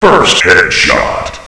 firstheadshot.wav